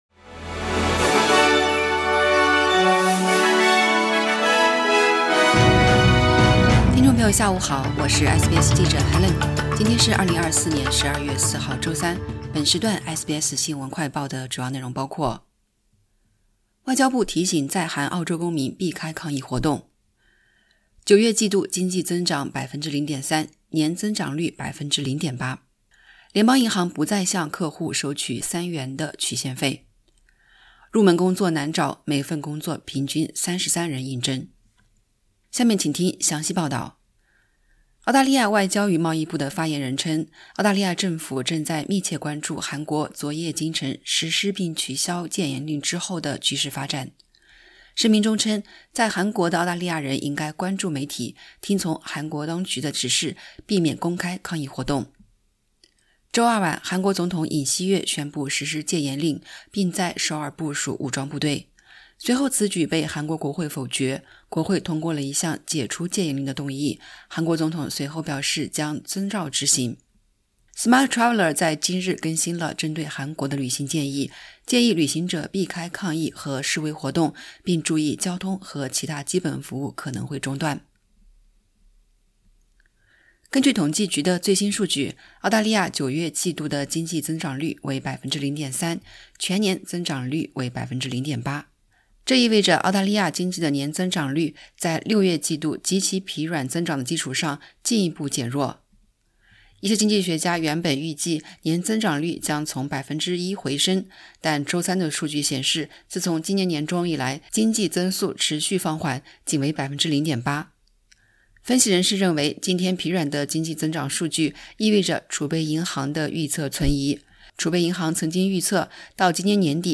【SBS新闻快报】外交部提醒在韩澳洲公民避开抗议活动